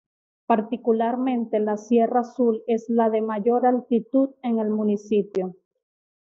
al‧ti‧tud
/altiˈtud/